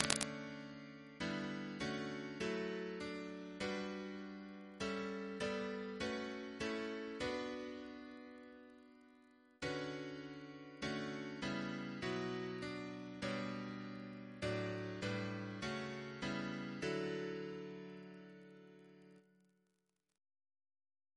Double chant in D Composer